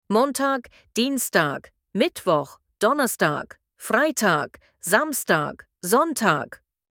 آشنایی با روزهای هفته به آلمانی و تلفظ صحیح آن‌ها